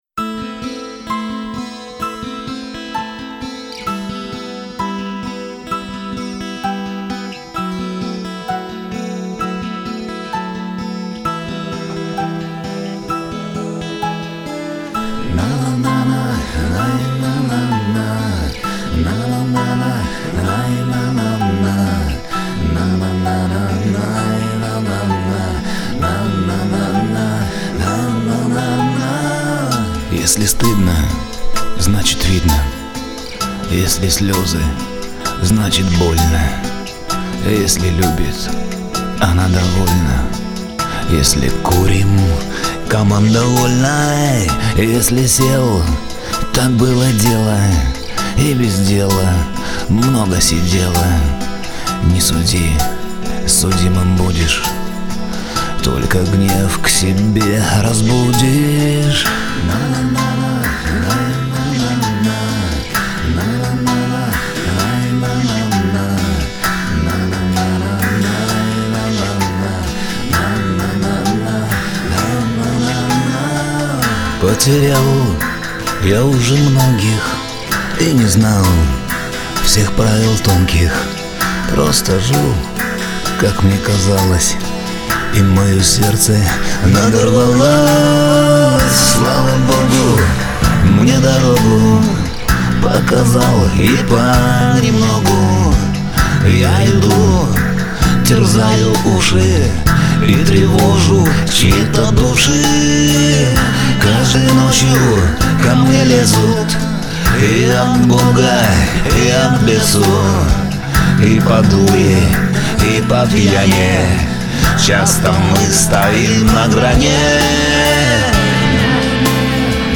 С концерта на Таганке в Альме на Старый Новый год 13.01.2012